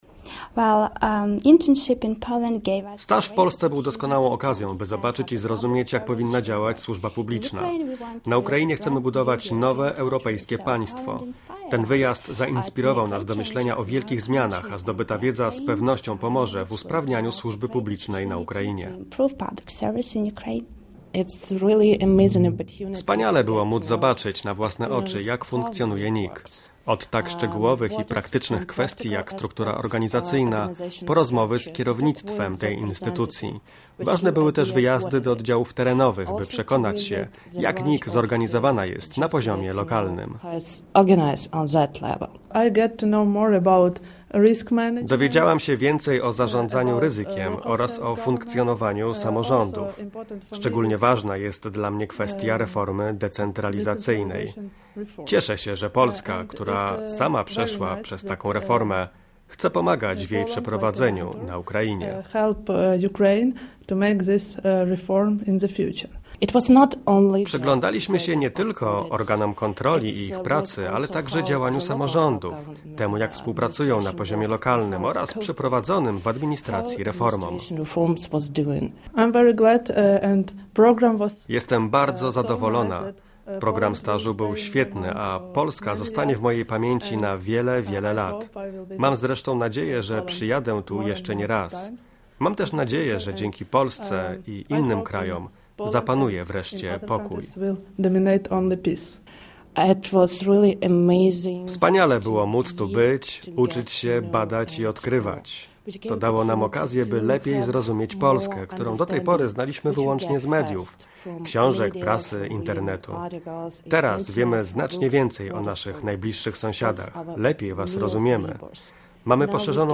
Na początku grudnia pięciu młodych Ukraińców odbyło w Warszawie i Krakowie serię szkoleń, spotkań i warsztatów. Wypowiedzi stażystów z Ukrainy Studenci z Ukrainy na stażu w NIK